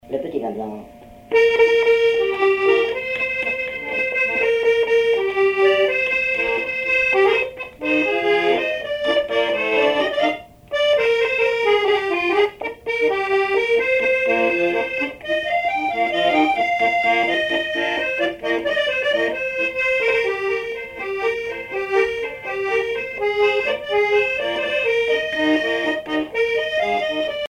accordéon(s), accordéoniste
valse musette
Pièce musicale inédite